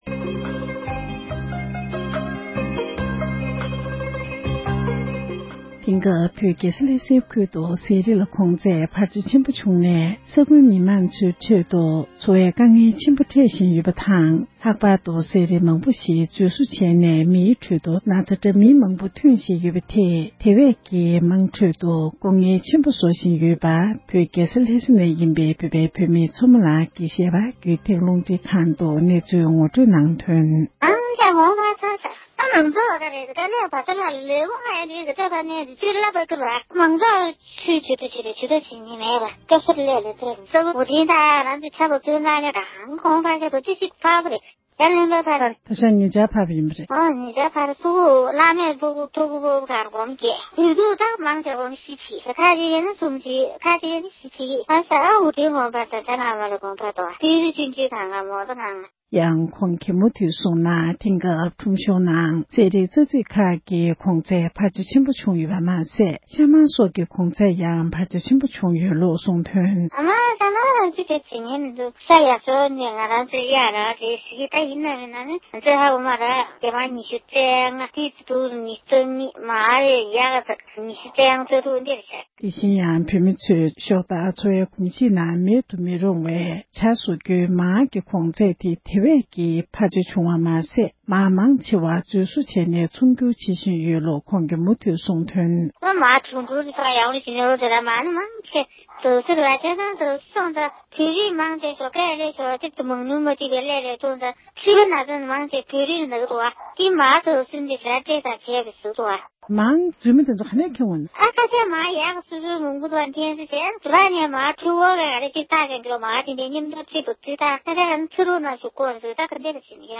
རྒྱལ་ས་ལྷ་སའི་ནང་བཅའ་བཞུགས་གནང་མཁན་བོད་མི་ཞིག་ལ་གནས་འདྲི་ཞུས་པར་གསན་རོགས